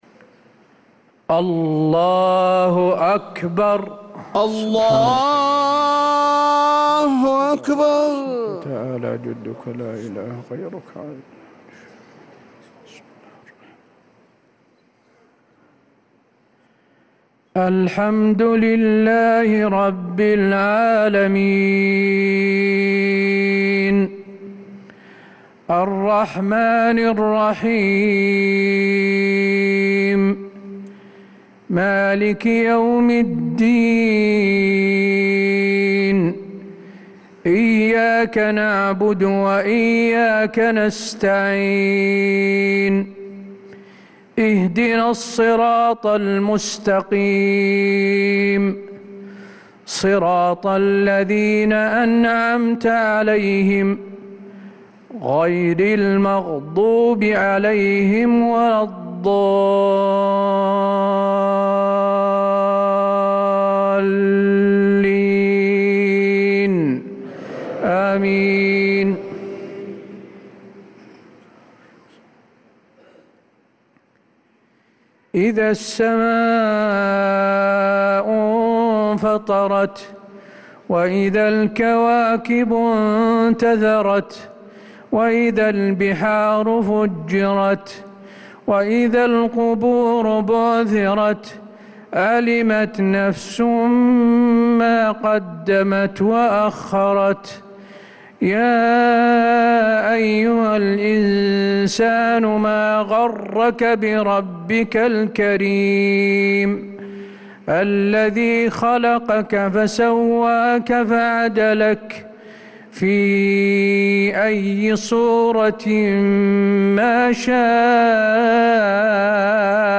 صلاة العشاء للقارئ حسين آل الشيخ 20 ربيع الأول 1446 هـ
تِلَاوَات الْحَرَمَيْن .